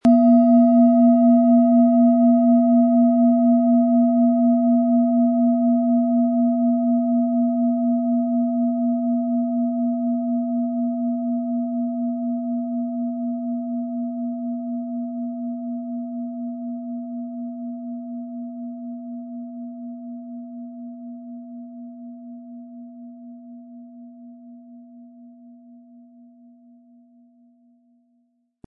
Tibetische Kopf-Herz-Bauch- und Schulter-Klangschale, Ø 13,9 cm, 320-400 Gramm, mit Klöppel
Mit dem beiliegenden Klöppel wird Ihre Klangschale mit schönen Tönen klingen.
MaterialBronze